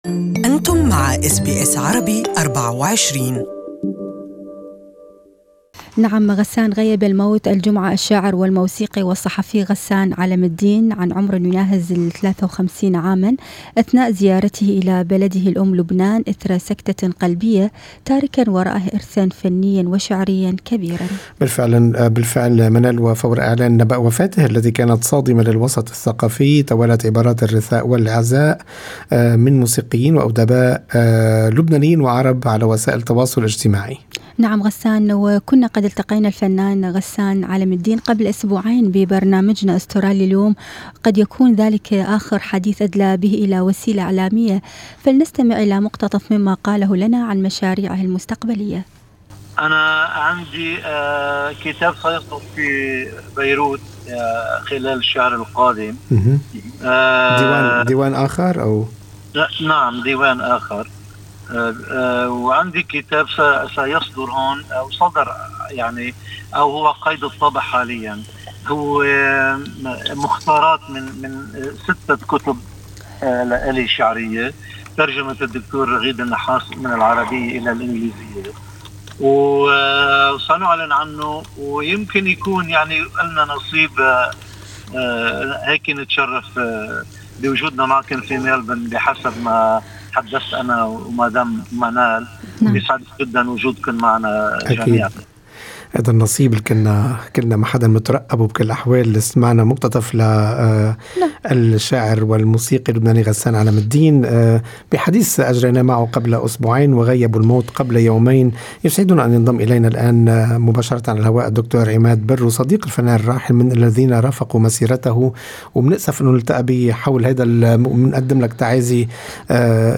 المزيد في لقاءِ مباشر